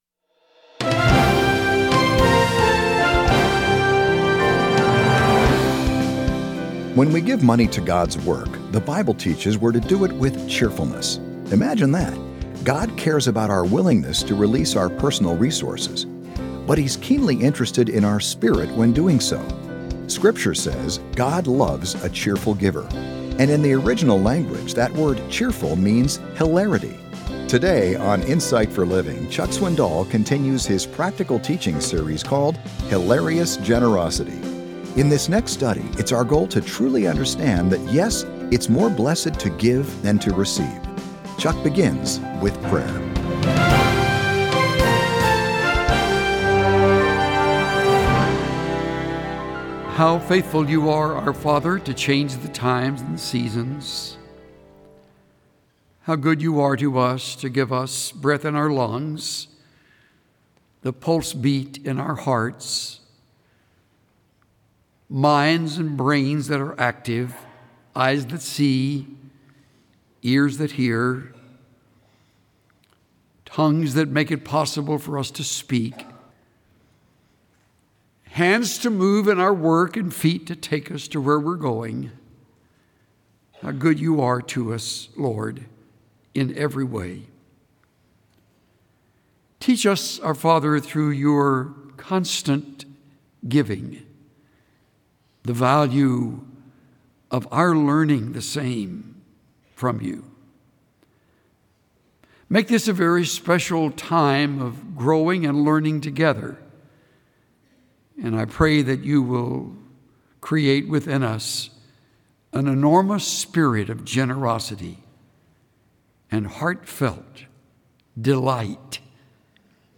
Today on Insight for Living, Chuck Swindall continues his practical teaching series called Hilarious Generosity.
Chuck begins with prayer.